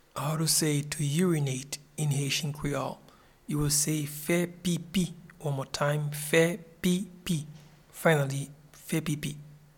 Pronunciation and Transcript:
To-urinate-in-Haitian-Creole-Fe-pipi.mp3